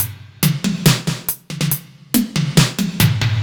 Index of /musicradar/french-house-chillout-samples/140bpm/Beats
FHC_BeatC_140-03_NoKick.wav